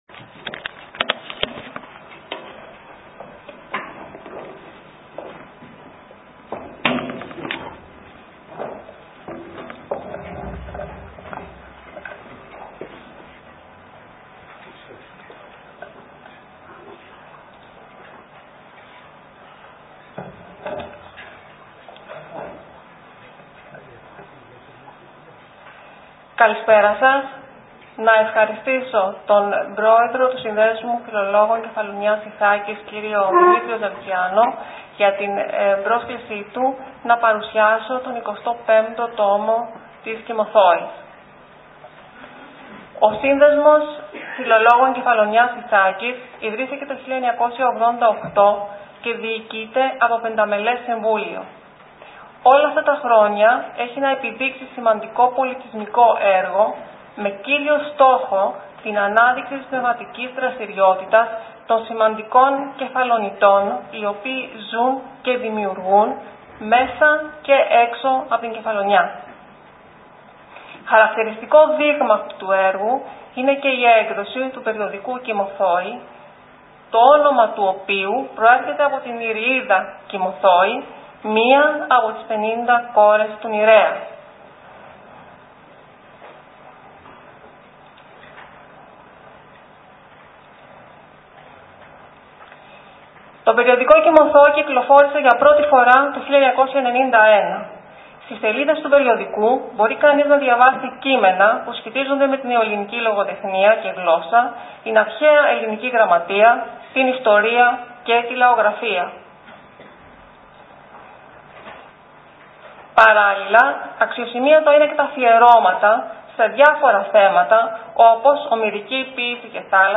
Παρουσίαση του 25ου Τόμου της ΚΥΜΟΘΟΗ, περιοδική έκδοση του Συνδέσμου Φιλολόγων Κεφαλονιάς και Ιθάκης στην Κοργιαλένειο Βιβλιοθήκη.
ΟΜΙΛΙΑ